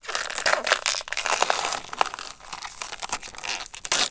ALIEN_Insect_05_mono.wav